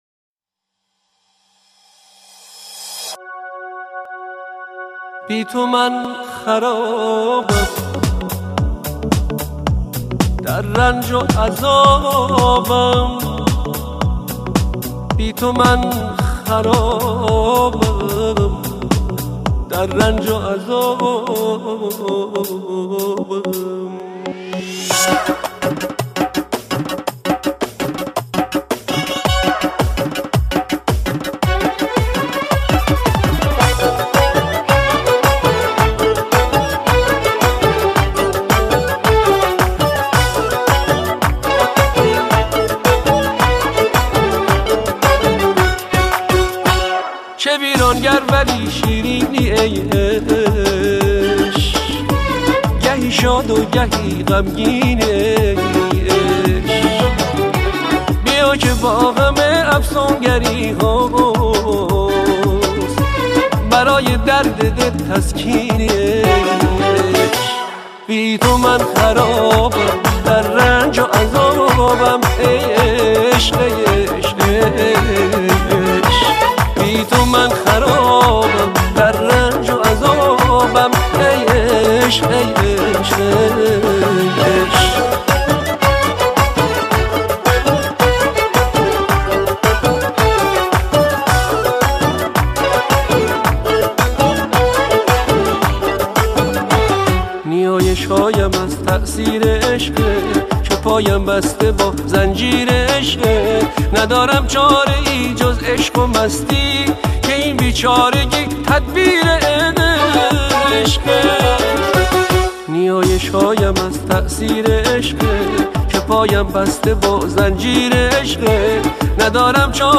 آهنگ احساسی